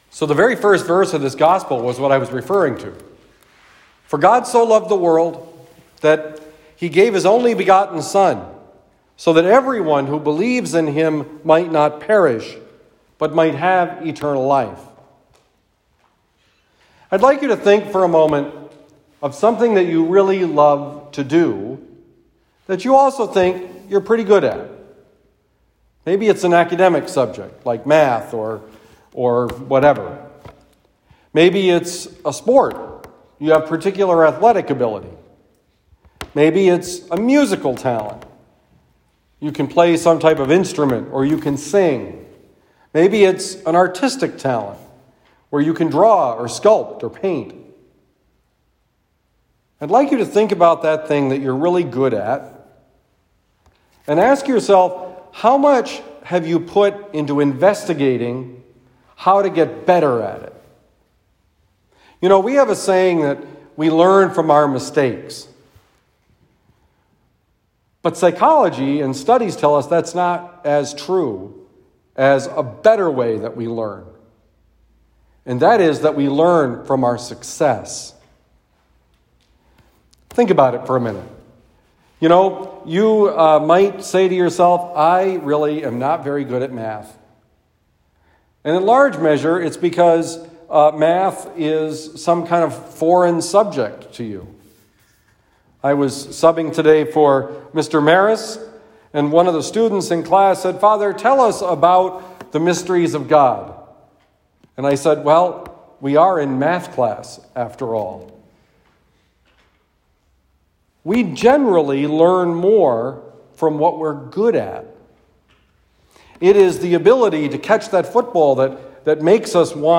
Homily for Wednesday, April 14, 2021
Given at LaSalle Retreat Center, Glencoe, Missouri.